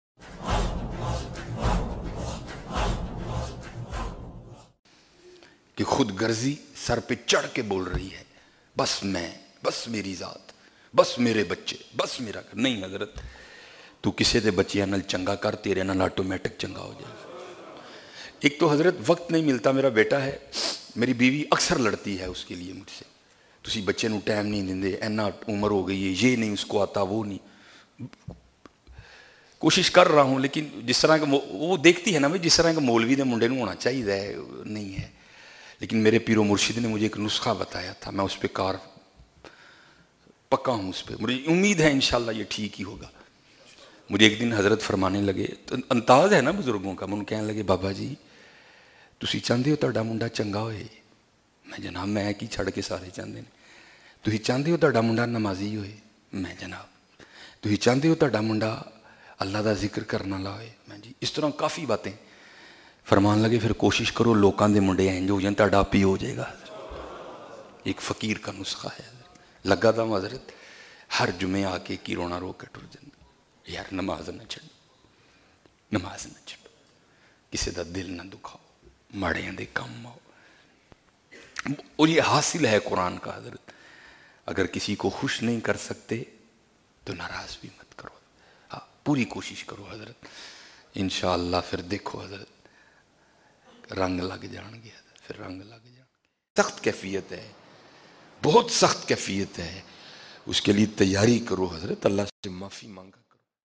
Agr Chahty hu bayan